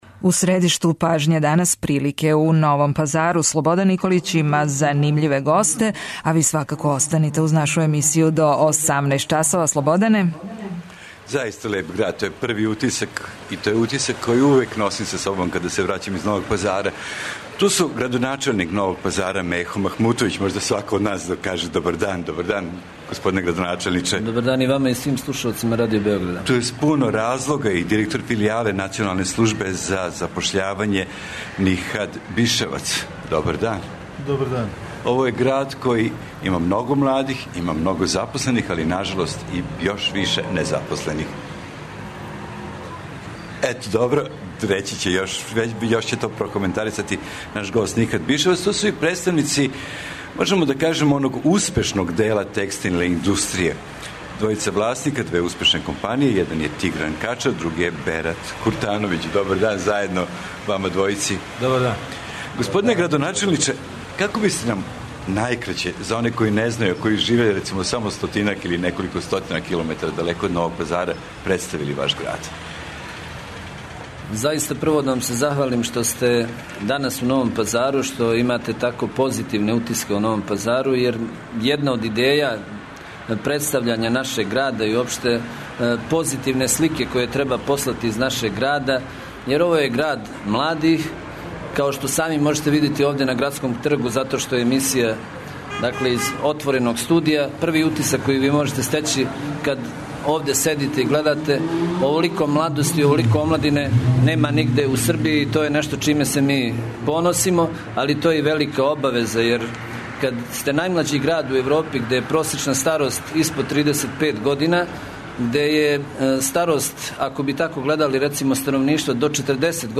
Ова емисија се реализује из Новог Пазара и из Београда. Нови Пазар је град са више незапослених него запослених грађана. Како решити овај проблем?